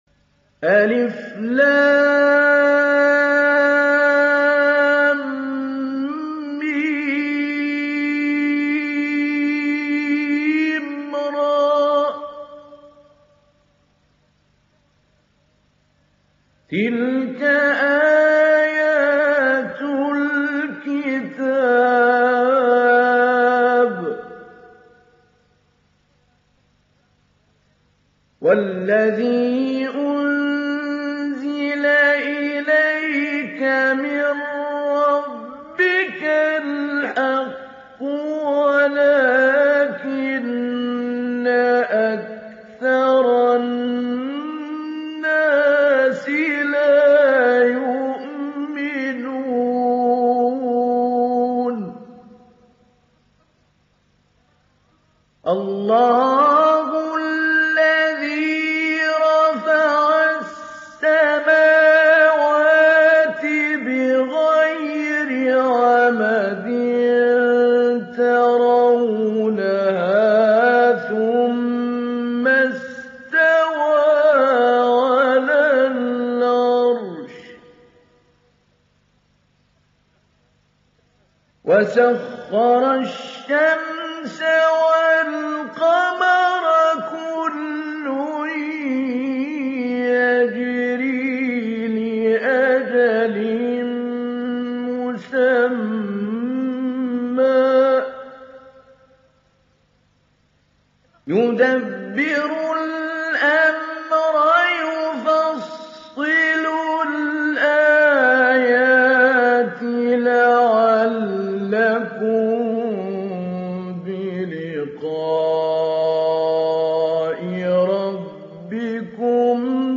İndir Rad Suresi Mahmoud Ali Albanna Mujawwad